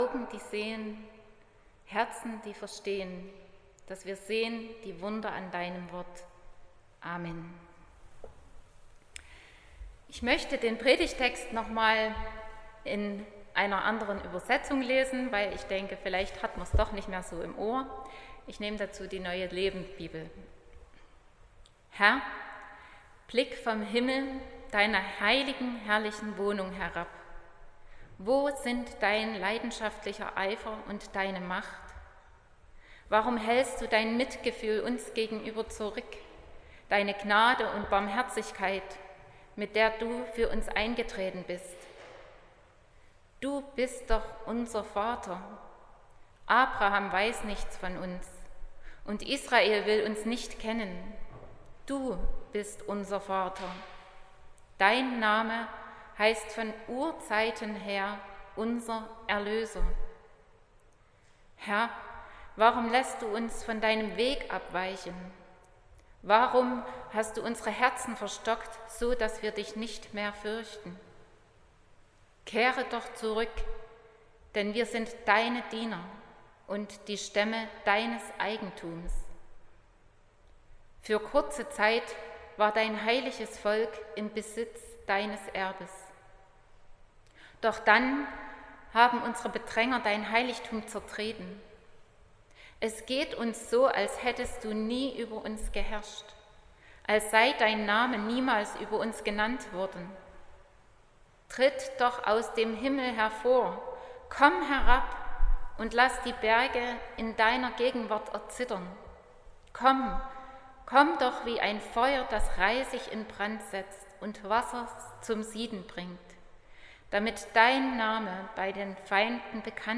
08.12.2024 – Gottesdienst
Predigt (Audio): 2024-12-08_Das_aengstliche_Herz.mp3 (24,5 MB)